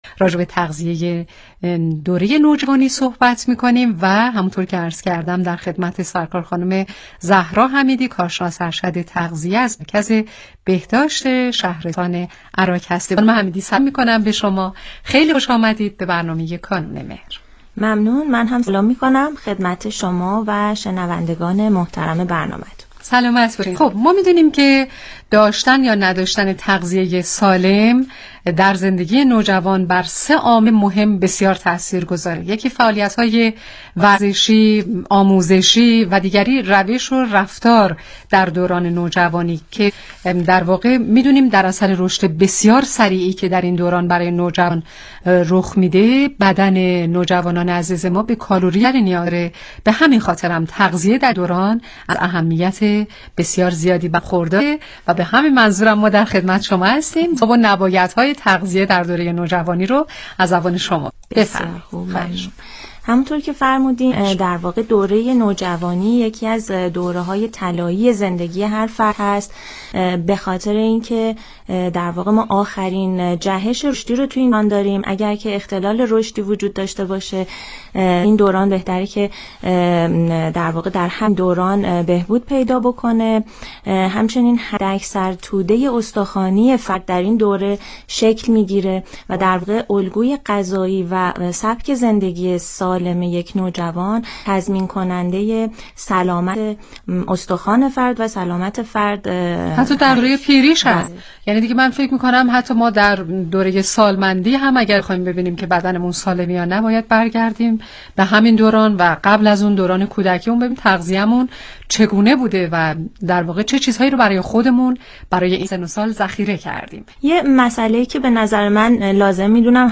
برنامه رادیویی کانون مهر با موضوع تغذیه در نوجوانان، ۱۵ آذر ماه